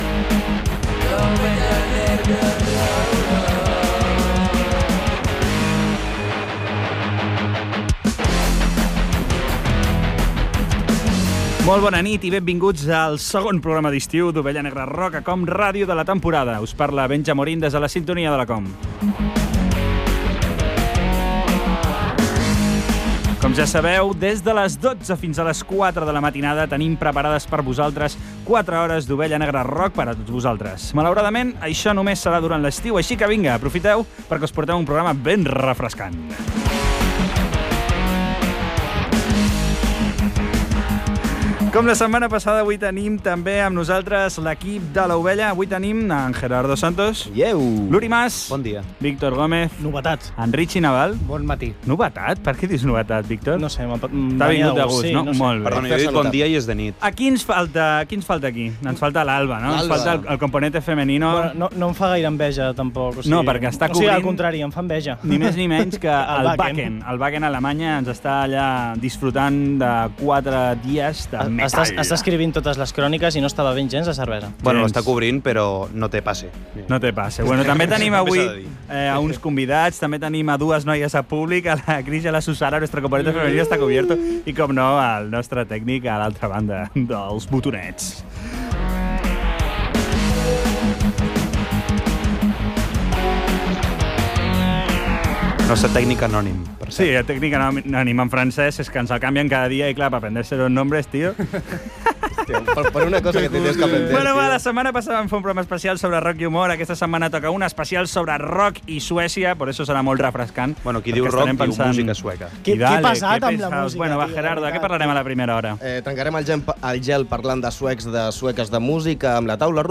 Inici del programa, crèdits i sumari Gènere radiofònic Musical